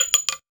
weapon_ammo_drop_09.wav